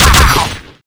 sentry_shoot2.wav